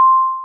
ding.wav